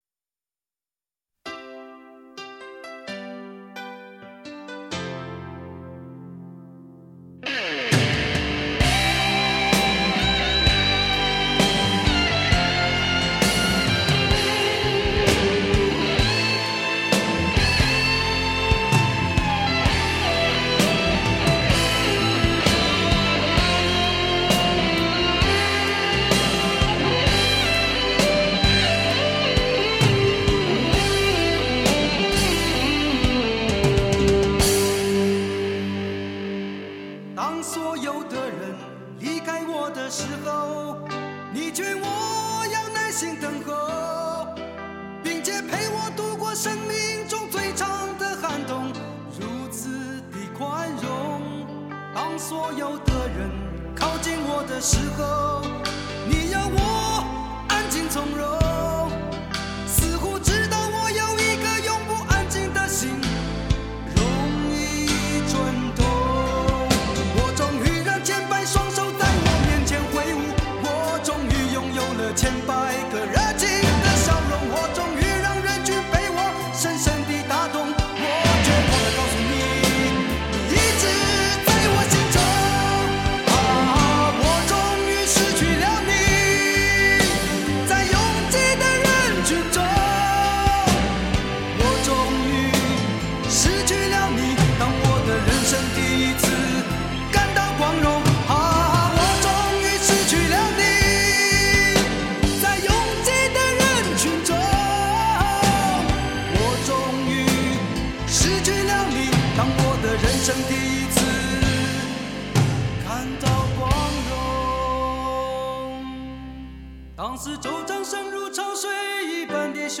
铁骨铮铮的硬派情歌